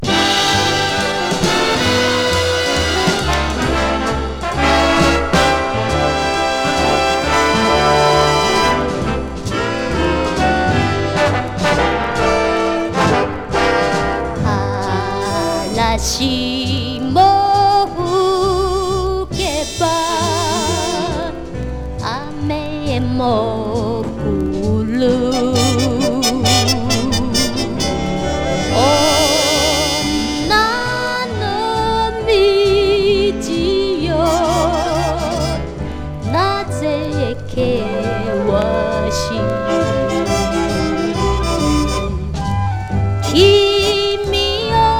Jazz, Vocal　USA　12inchレコード　33rpm　Stereo